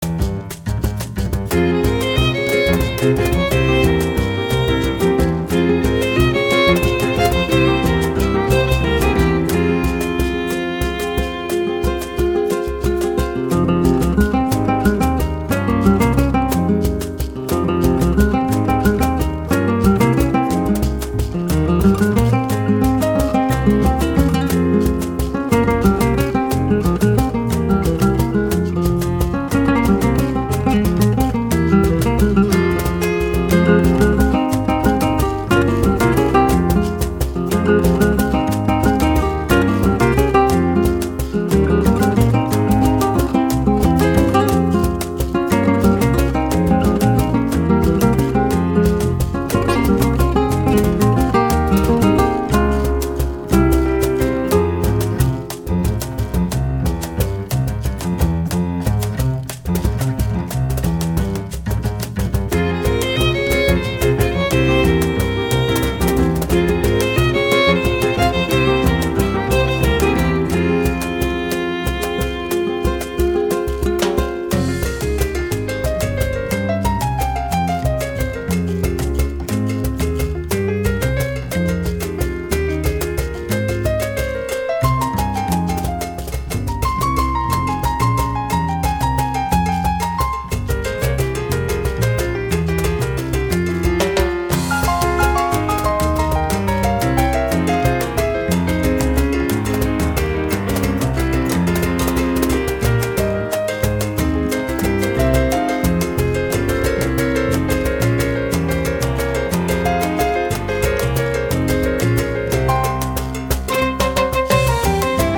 Música popular: instrumental y jazz